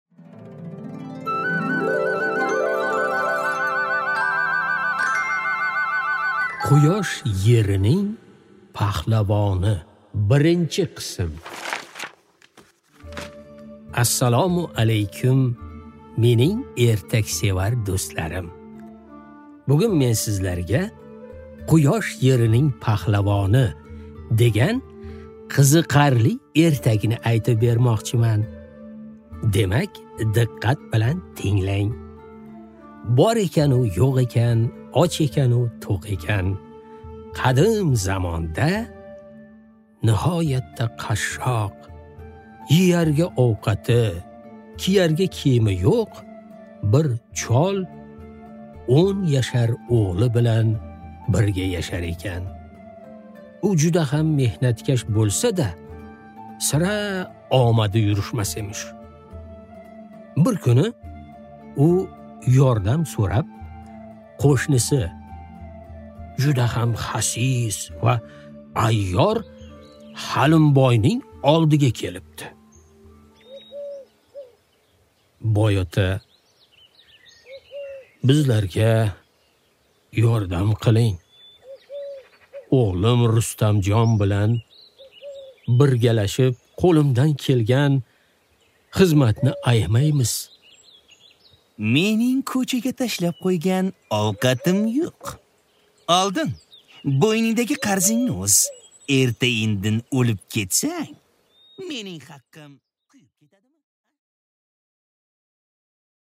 Аудиокнига Quyosh yerining pahlavoni